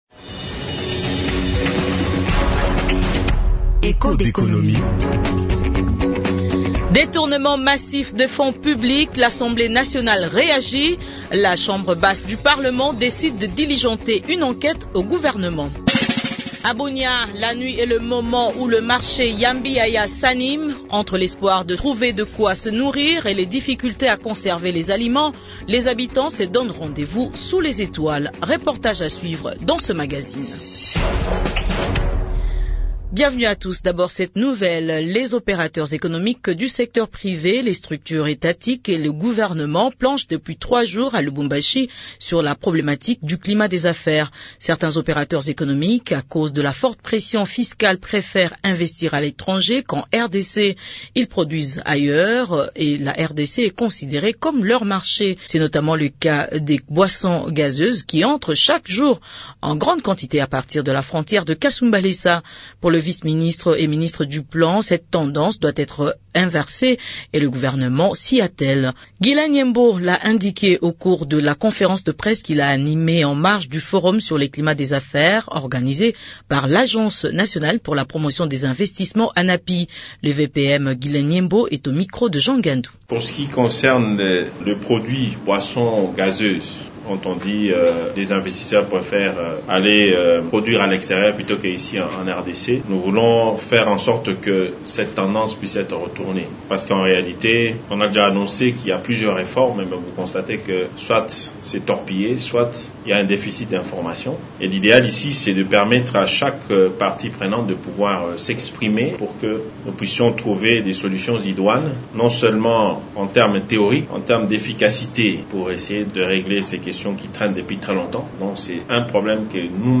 Reportage à suivre dans ce numéro du magazine Echos d'économie.